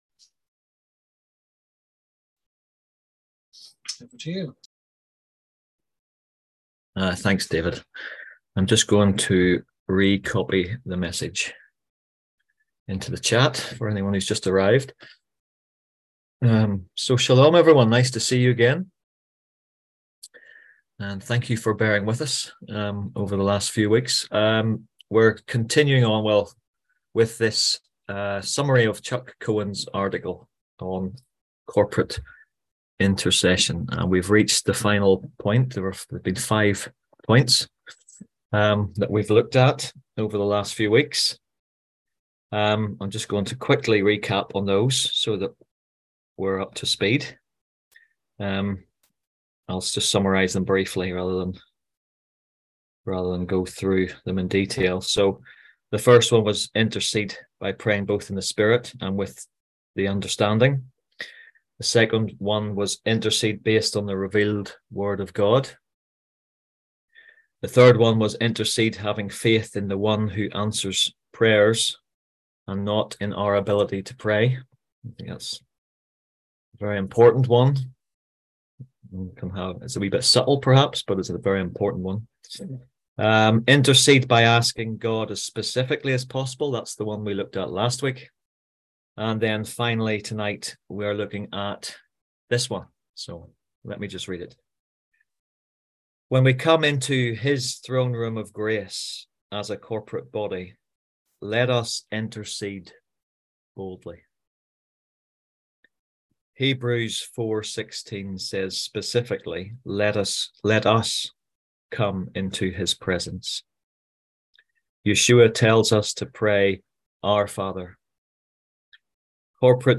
On June 12th at 7pm – 8:30pm on ZOOM